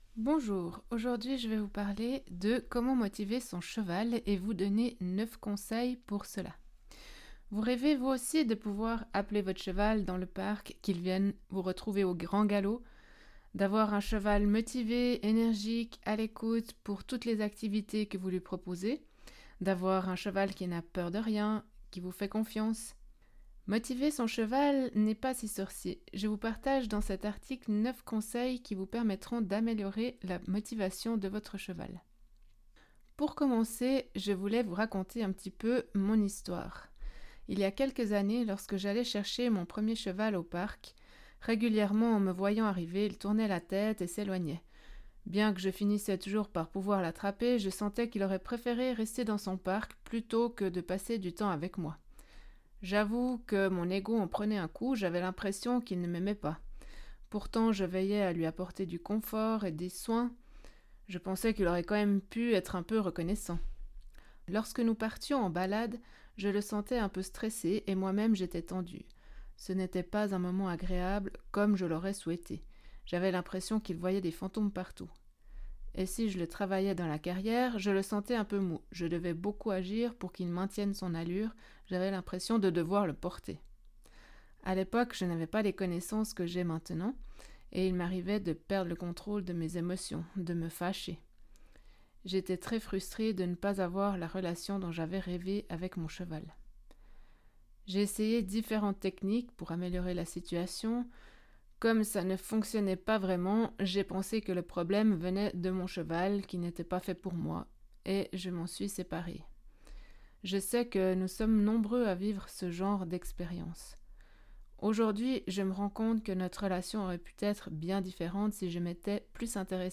Pour écouter cet article à la manière d’un podcast tout en vacant à une autre activité, ça se passe ici !